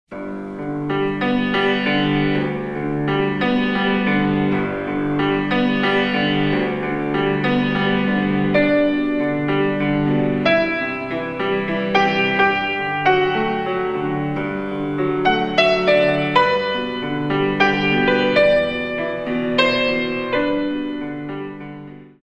In 3